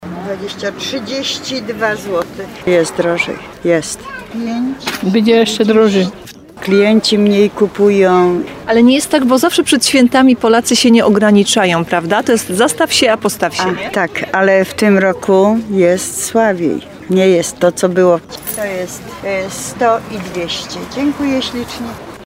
Jest drogo – słyszymy na innym stoisku.